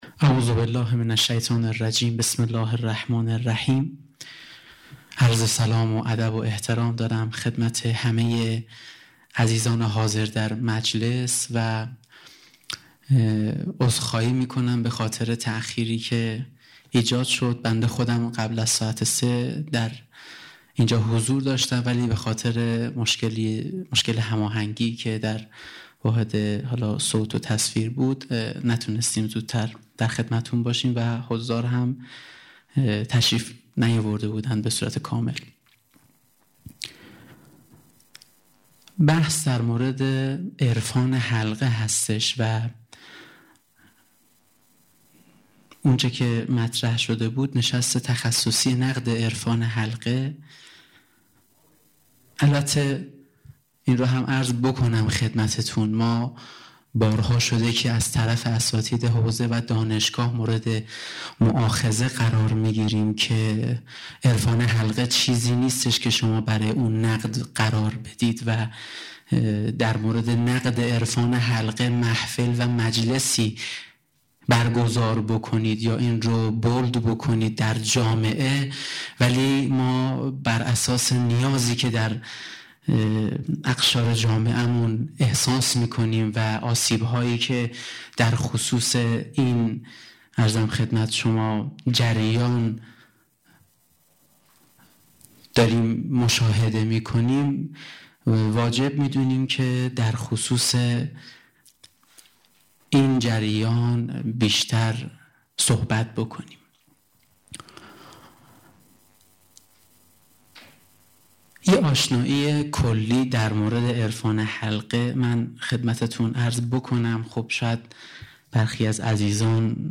دانلود سخنرانی استاد رائفی پور"اهمیت کار تربیتی برای امام زمان" دانشگاه فرهنگیان گرگان، 2 اسفند 94